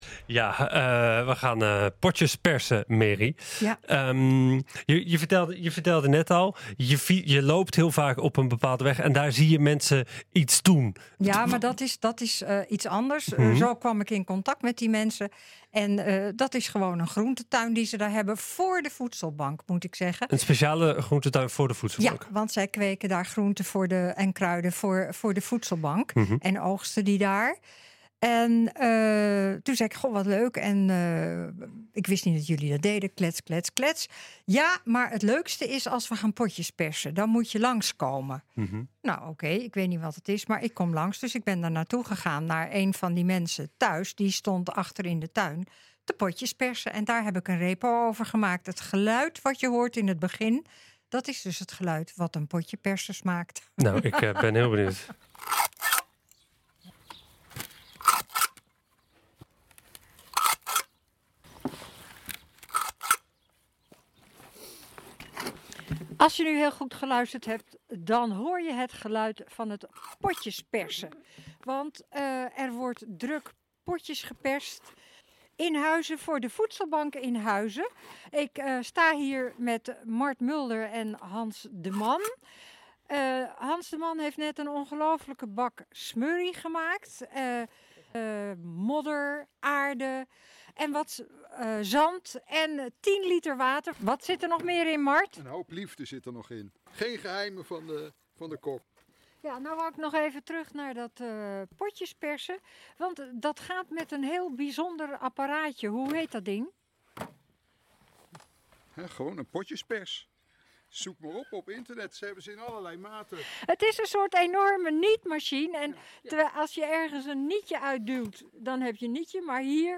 Daar is ze natuurlijk op in gegaan en ze maakte de volgende reportage.